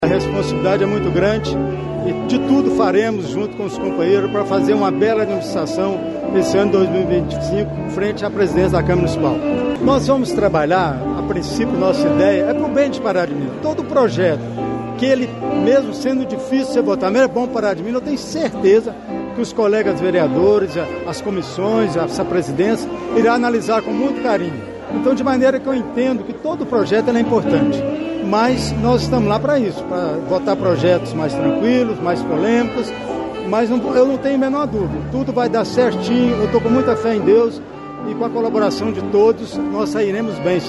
Em entrevista, o vereador Délio Alves falou sobre os desafios e projetos à frente da presidência e destacou a união entre legislativo e executivo em benefício da população: